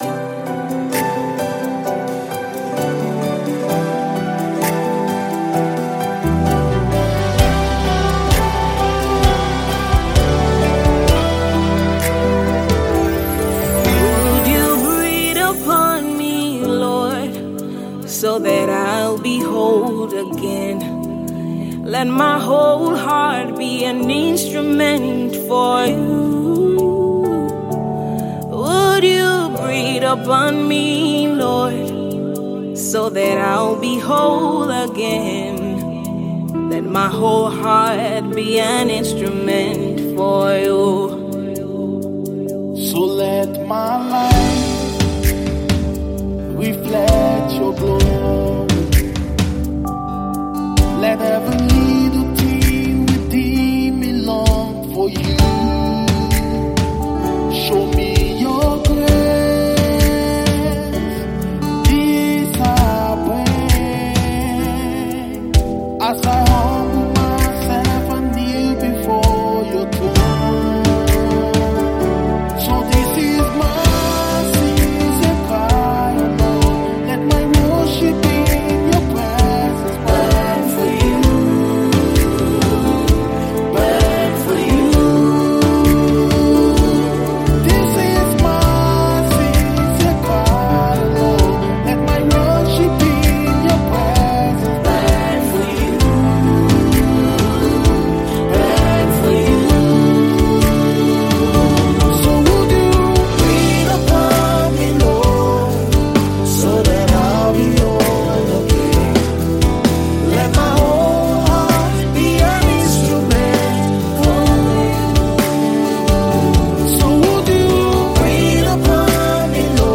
gospel
It is a song of worship and supplication.